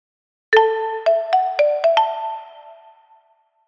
Ljudmärke